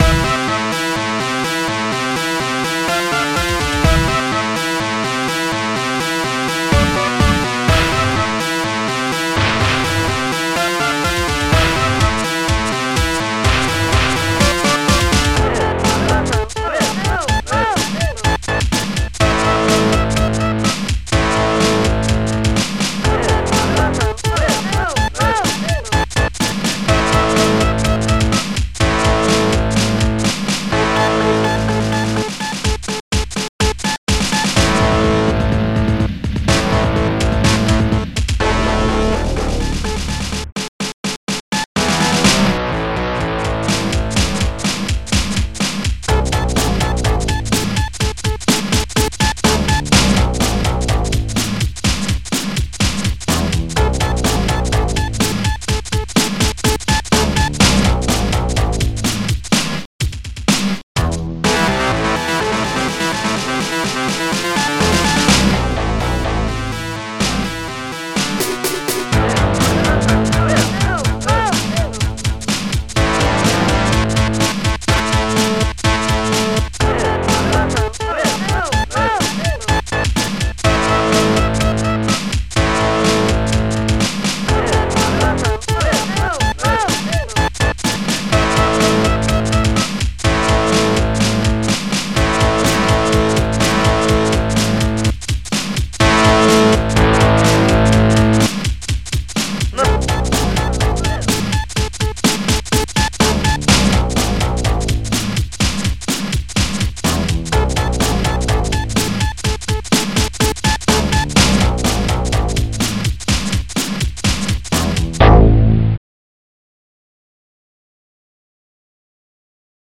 OctaMED Module